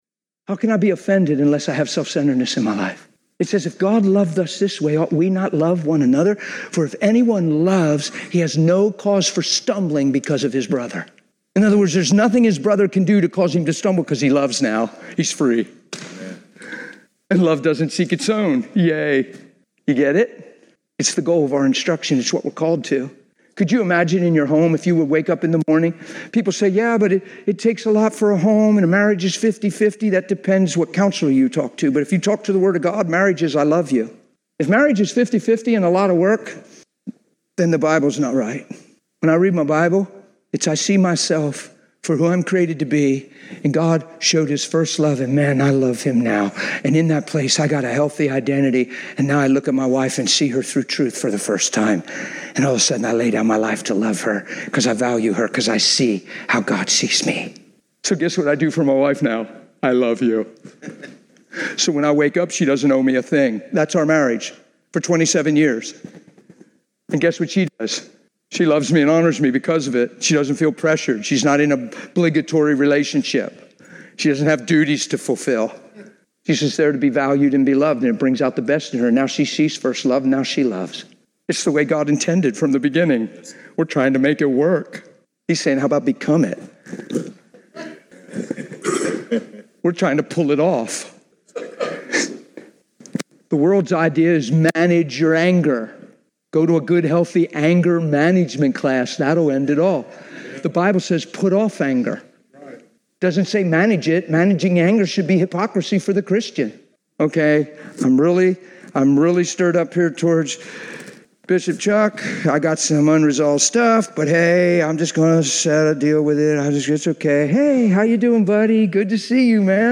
Service Type: Conference